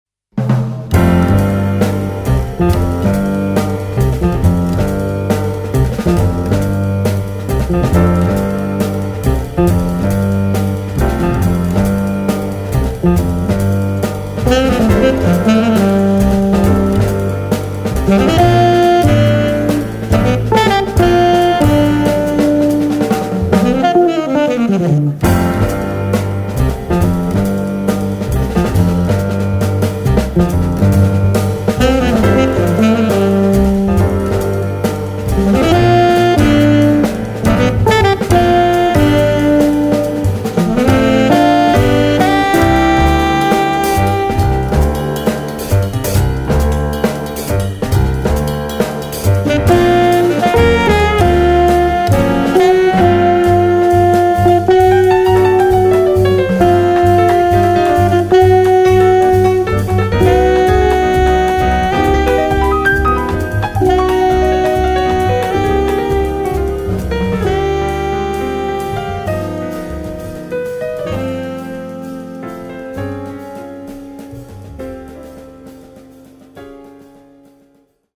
guitar
sax
piano
bass
drums